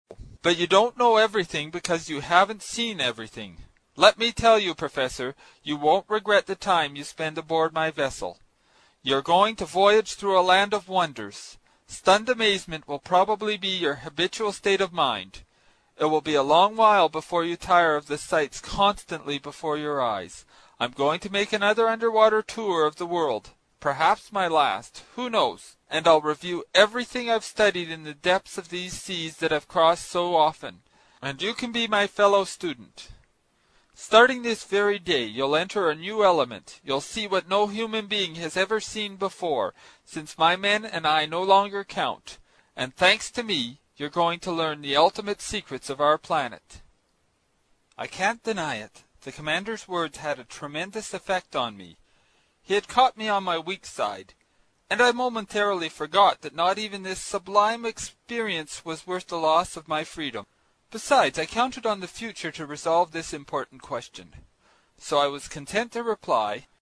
英语听书《海底两万里》第141期 第10章 水中人(12) 听力文件下载—在线英语听力室
在线英语听力室英语听书《海底两万里》第141期 第10章 水中人(12)的听力文件下载,《海底两万里》中英双语有声读物附MP3下载